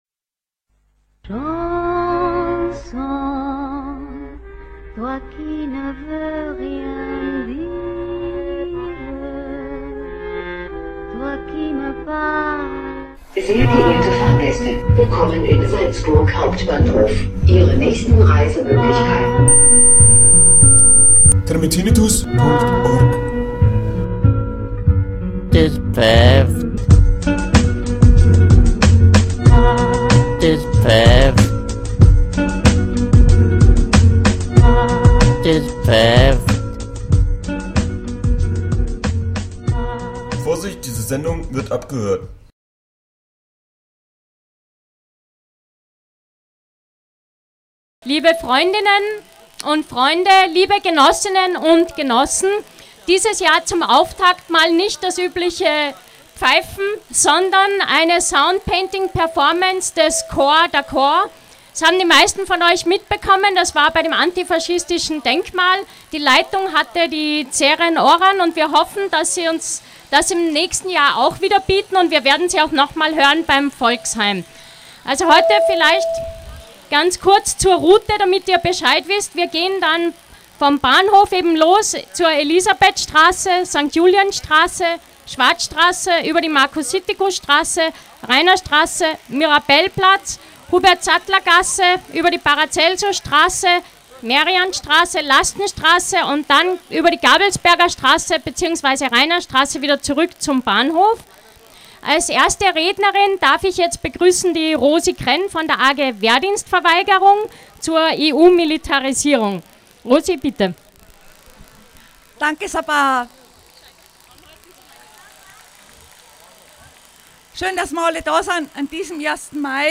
Beiträge von der 1. Mai Demo 2013
Anmerkung der Redaktion: Der Redebeitrag des Infoladen Salzburg ist nicht in der Liste und in der Datei, weil sie uns gebeten haben, das Aufnahmegerät während dessen auszuschalten.